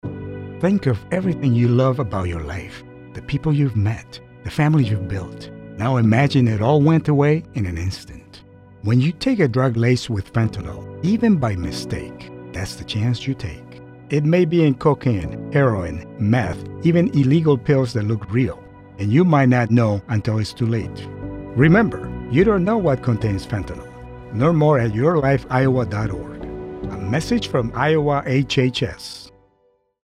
:30 Radio Spot | Fentanyl | 30+ Male